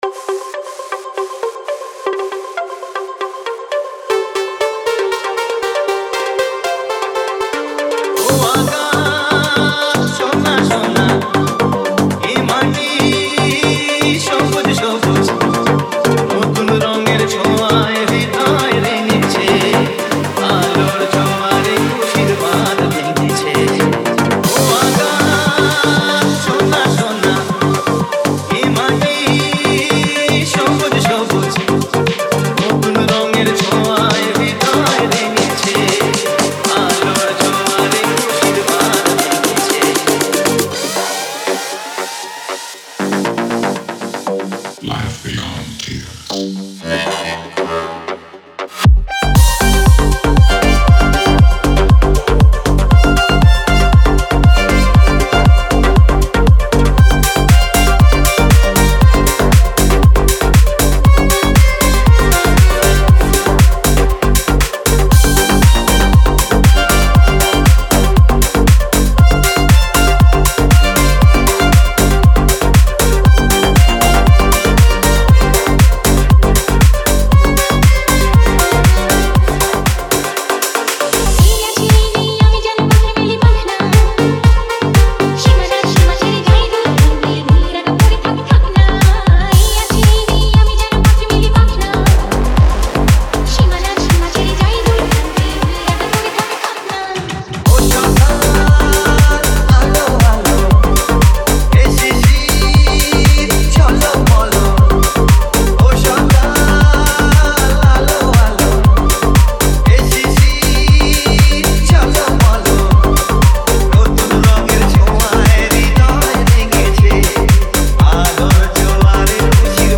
2025 Tollywood Single Remixes Song Name